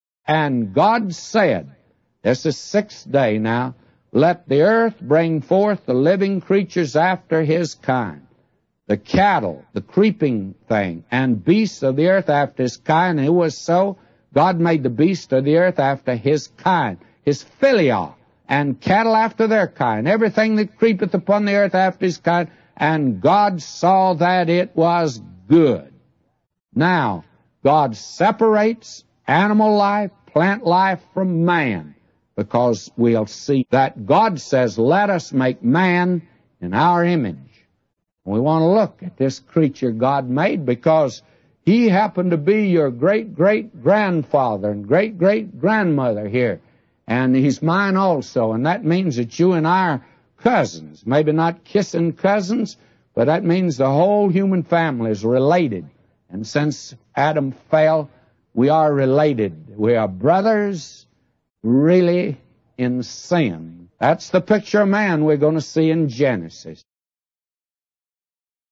The sermon explores God's creation of living beings, the unique status of humanity, and the implications of sin on our shared existence.